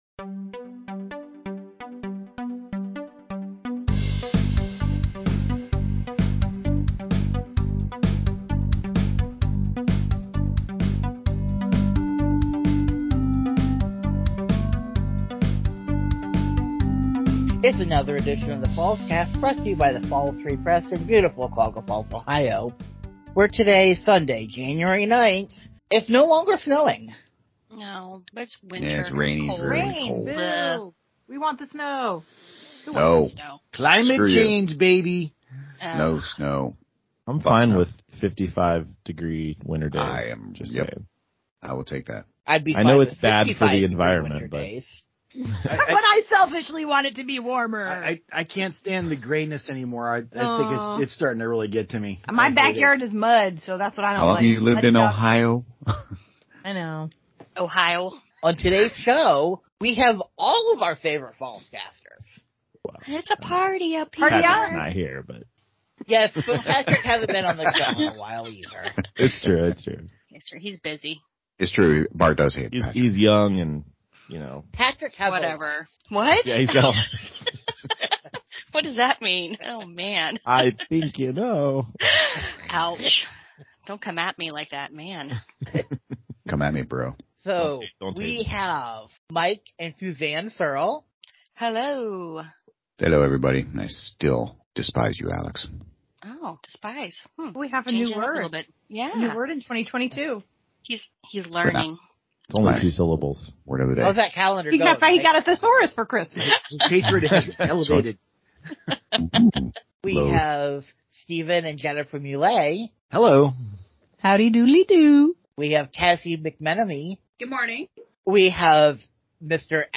This week’s show features nearly a full staff of characters discussing: